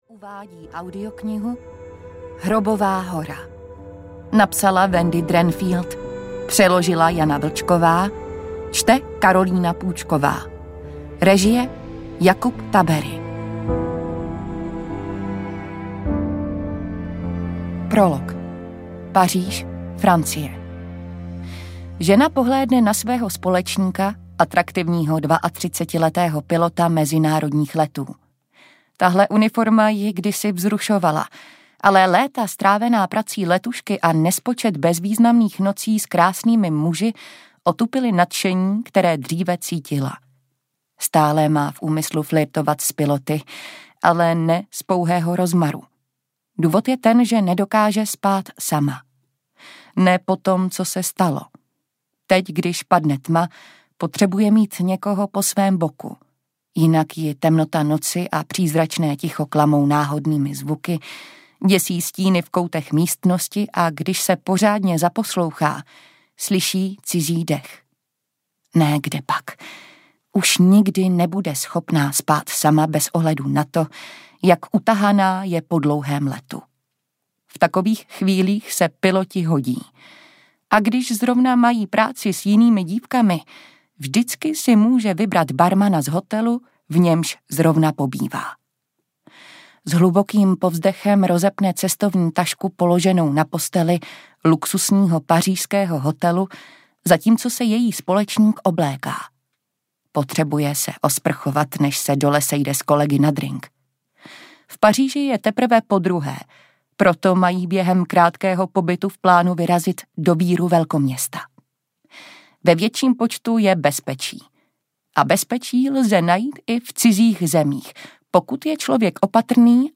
Hrobová hora audiokniha
Ukázka z knihy